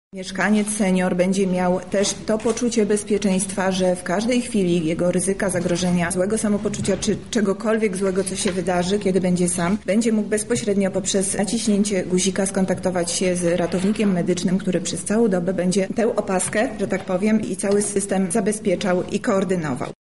O tym, do czego służy taka opaska mówi  Monika Lipińska, zastępczyni prezydenta miasta: